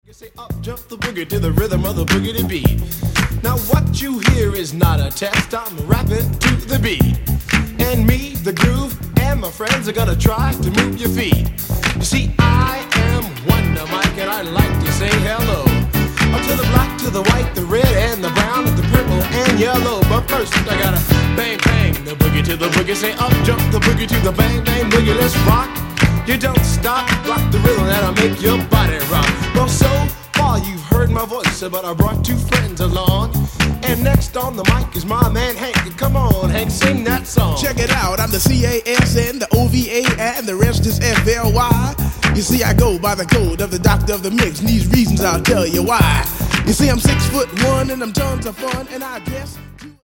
Genere:   Disco | Pop | New Wave